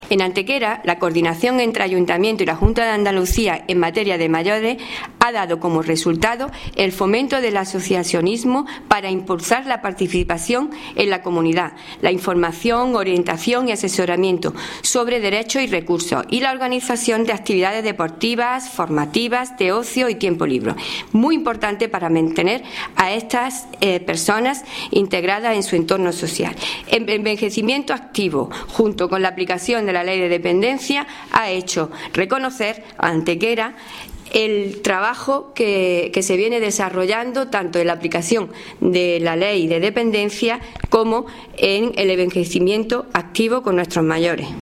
Cortes de voz
Audio: concejala de Derechos Sociales   519.18 kb  Formato:  mp3